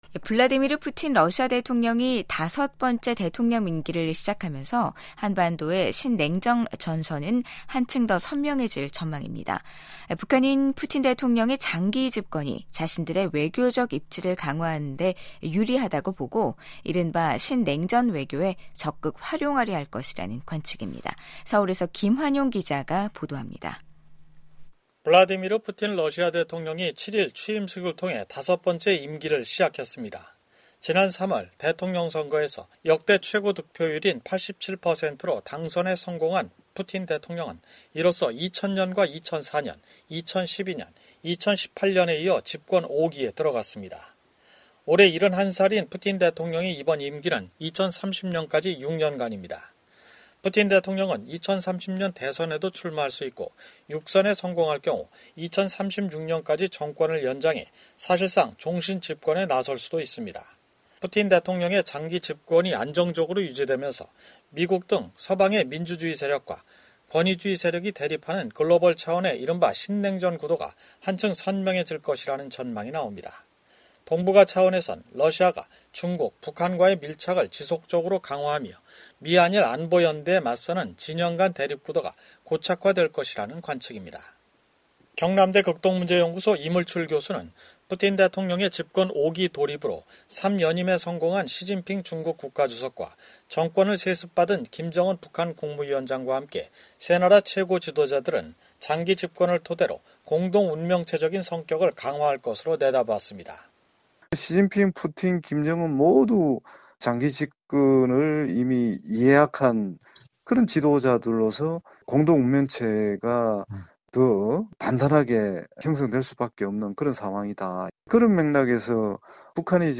블라디미르 푸틴 러시아 대통령이 다섯번째 대통령 임기를 시작하면서 한반도의 신냉전 전선은 한층 더 선명해질 전망입니다. 북한은 푸틴 대통령의 장기 집권이 자신들의 외교적 입지를 강화하는 데 유리하다고 보고 이른바 ‘신냉전 외교’에 적극 활용하려 할 것이라는 관측입니다. 서울에서